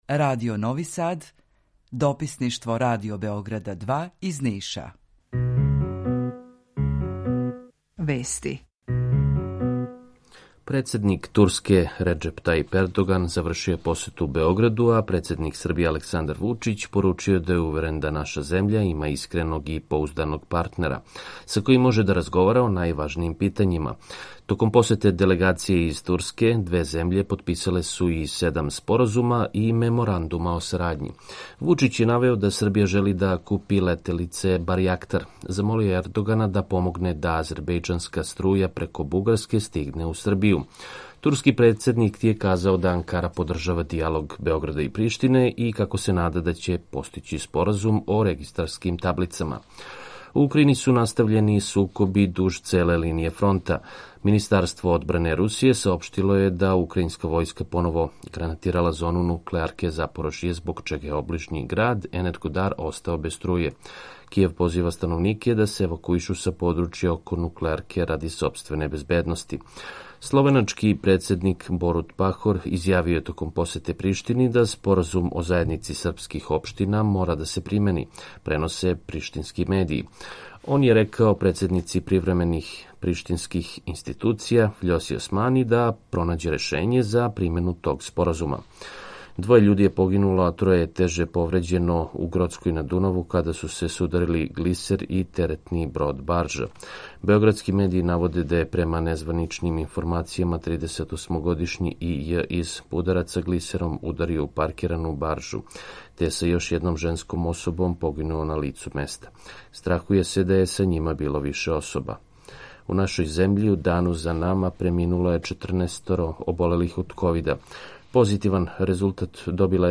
Jутарњи програм заједнички реализују Радио Београд 2, Радио Нови Сад и дописништво Радио Београда из Ниша. Cлушаоци могу да чују најновије информације из сва три града, најаве културних догађаја, теме које су занимљиве нашим суграђанима без обзира у ком граду живе.
У два сата, ту је и добра музика, другачија у односу на остале радио-станице.